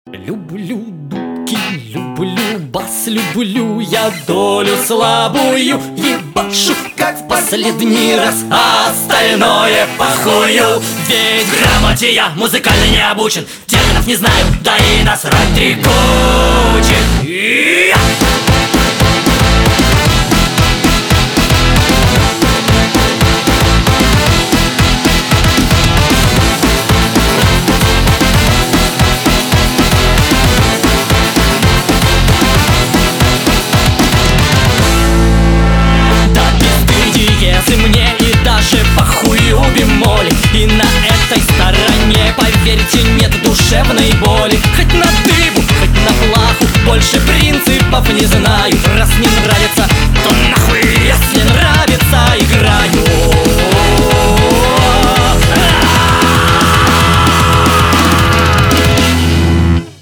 • Качество: 320, Stereo
позитивные
веселые
быстрые
ска
ска-панк